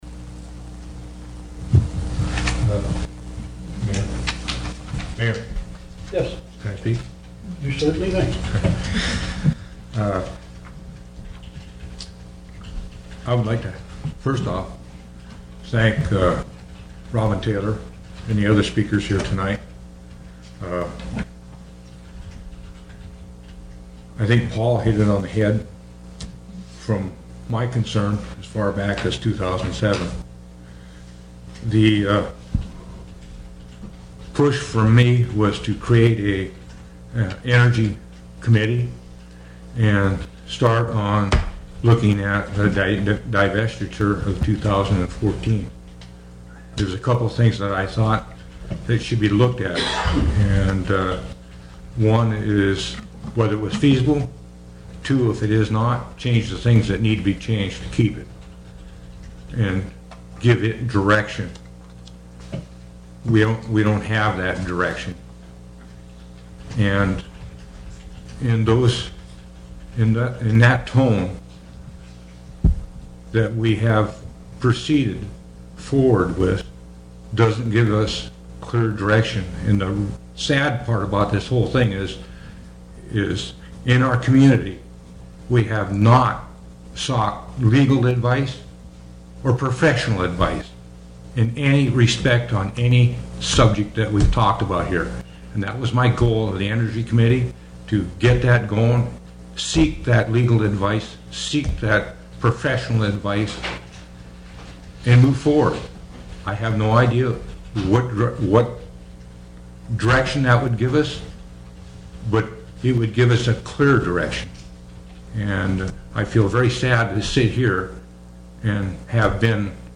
September 26, 2013 Borough Assembly Meeting - KSTK
City and Borough of Wrangell Borough Assembly Meeting AGENDA September 24, 2013 – 7:00 p.m. Location: Assembly Chambers, City Hall Click here to read the agenda.